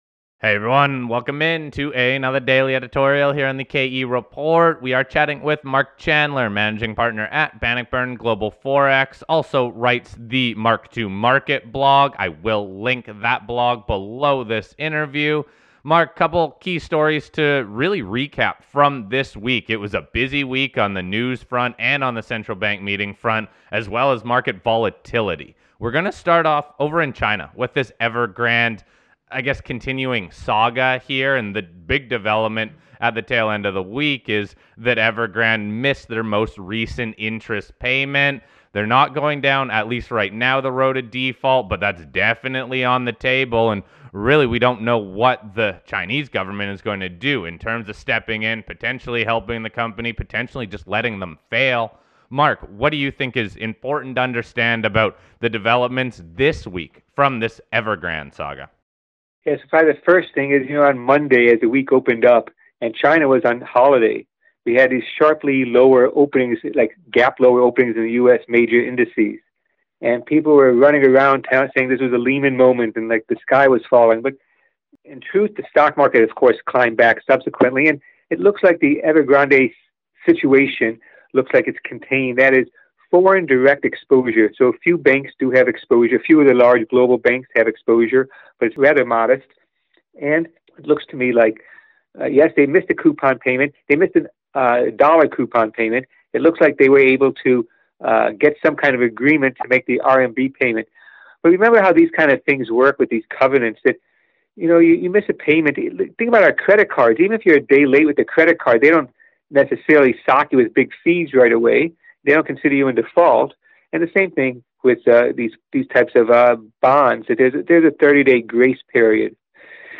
This interview was recorded on Friday afternoon.